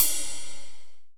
D2 RIDE-07-L.wav